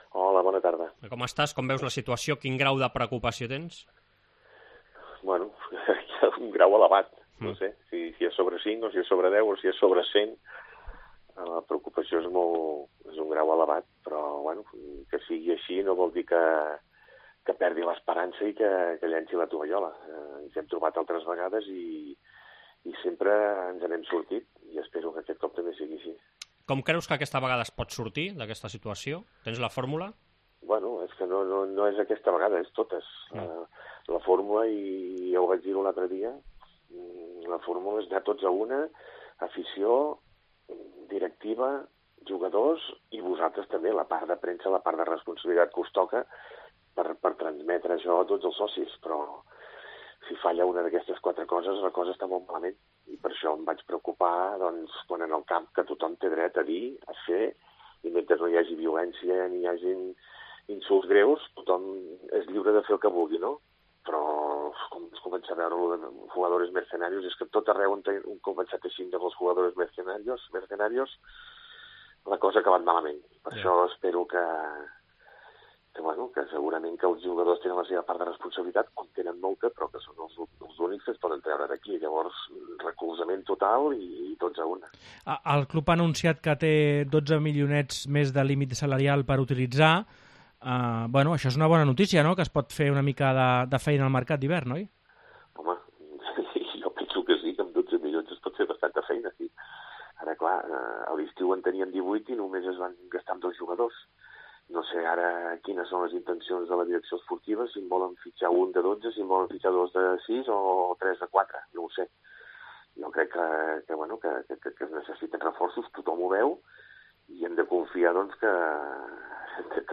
Entrevista amb l'expresident del RCDE Espanyol que demana màxim encert al mercat d'hivern per evitar el descens de l'equip